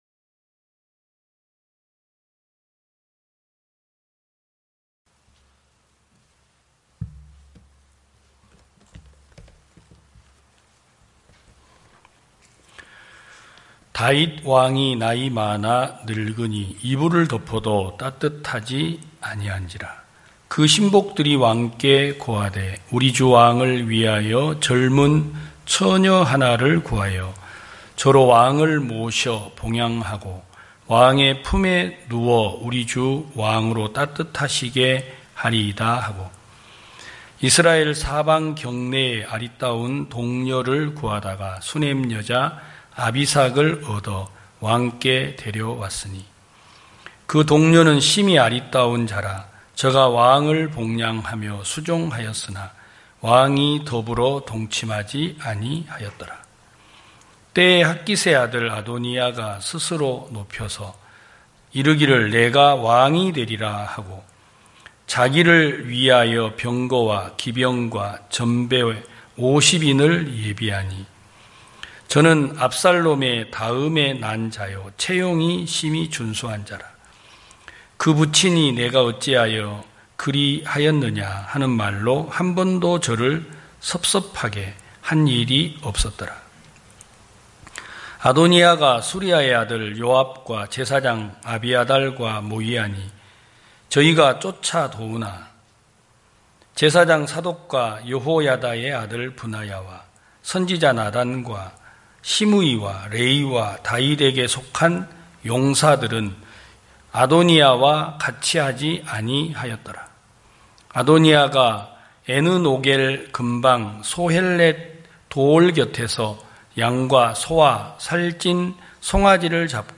2021년 8월 29일 기쁜소식부산대연교회 주일오전예배
성도들이 모두 교회에 모여 말씀을 듣는 주일 예배의 설교는, 한 주간 우리 마음을 채웠던 생각을 내려두고 하나님의 말씀으로 가득 채우는 시간입니다.